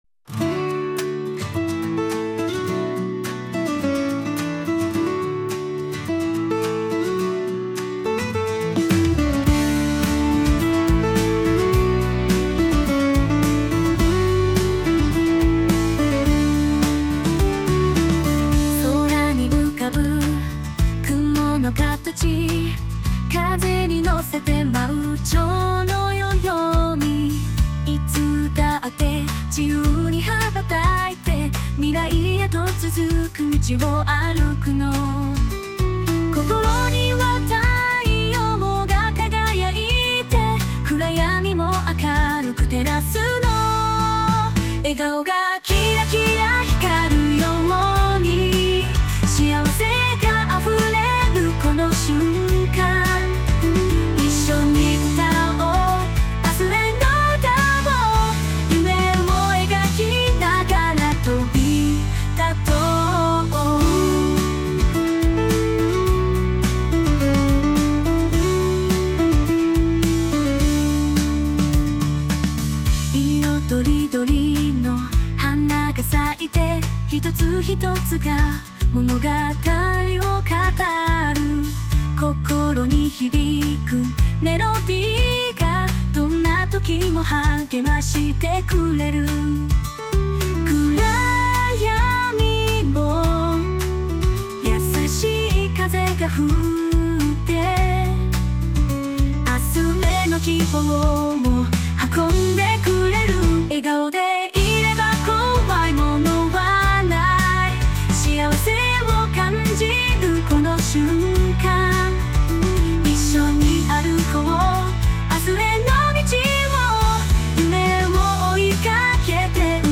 著作権フリーオリジナルBGMです。
女性ボーカル（邦楽・日本語）曲です。
今回の楽曲は、日本語の女性ボーカルで、アップテンポで元気な曲なお届けしたくて作りました✨